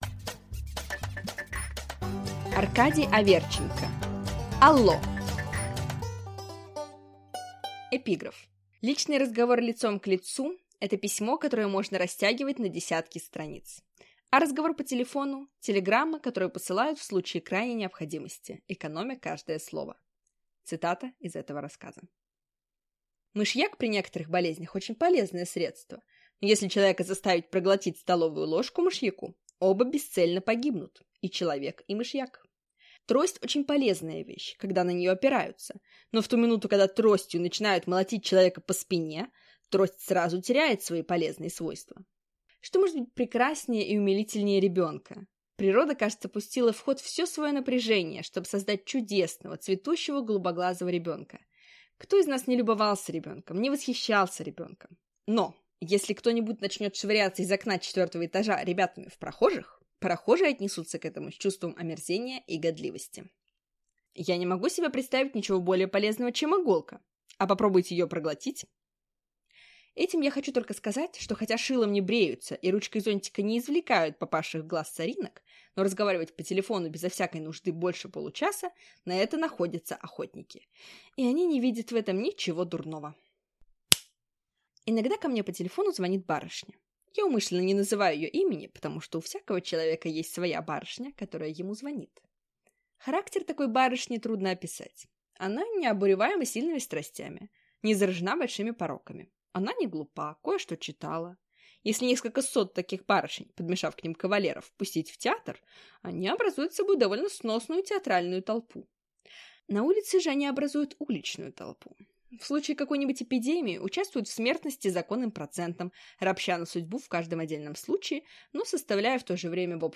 Аудиокнига Алло!